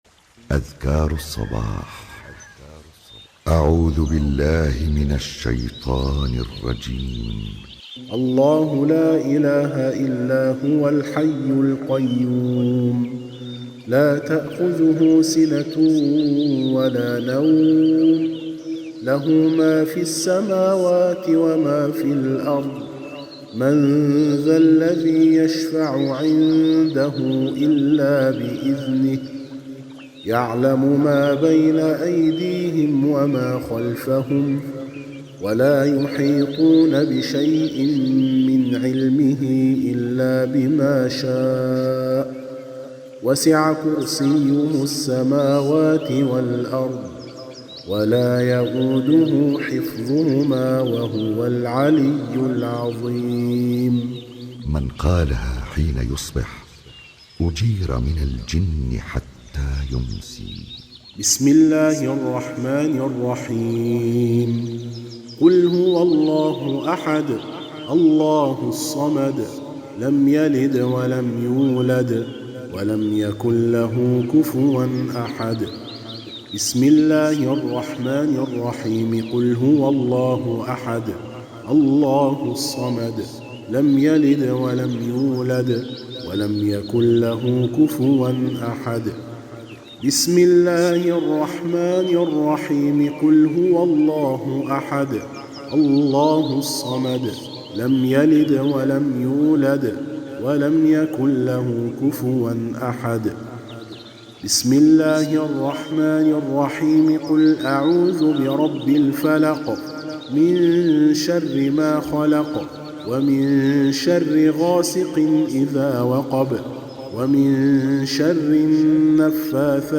تلاوة هادئة لأذكار المساء المأثورة من الكتاب والسنة